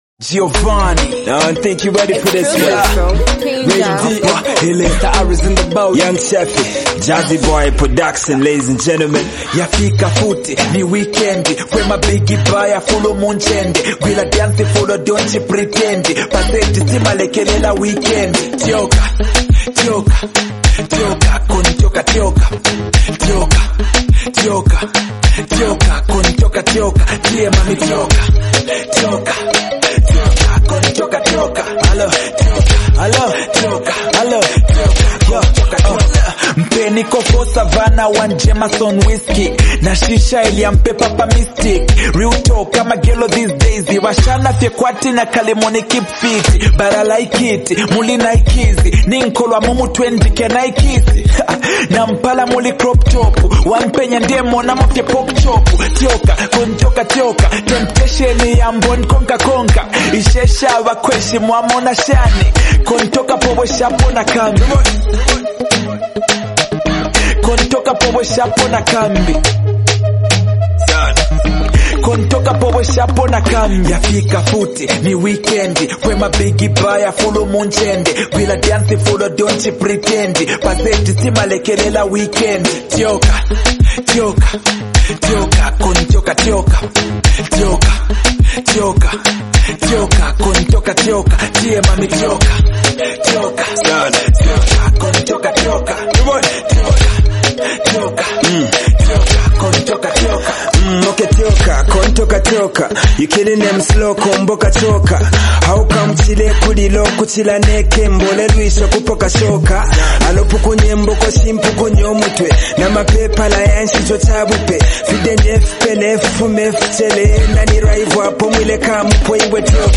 hard-hitting and motivational song